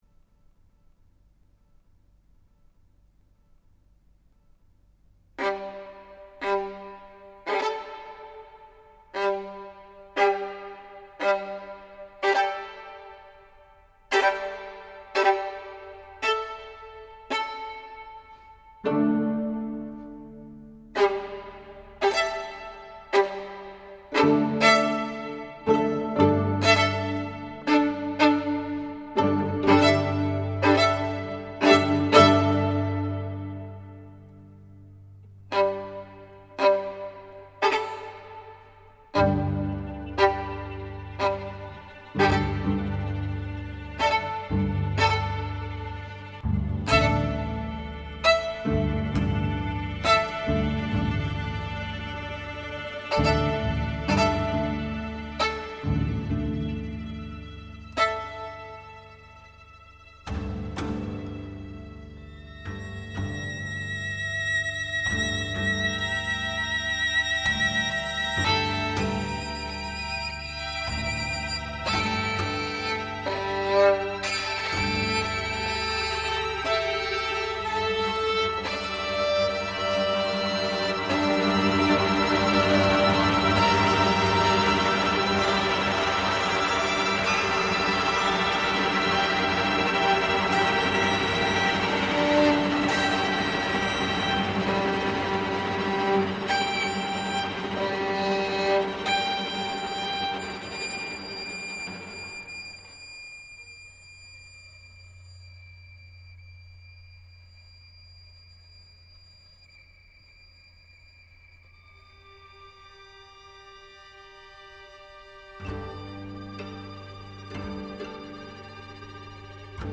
Outtakes from recording session at All Saint’s Tooting.
for violin and strings